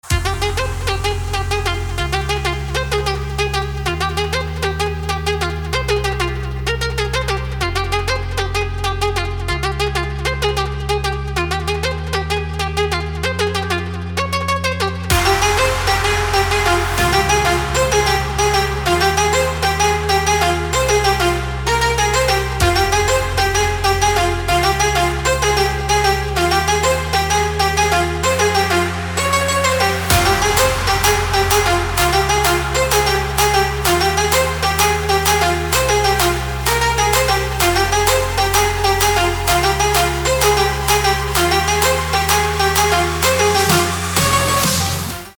красивые
dance
электронная музыка
без слов
club
Big Room
house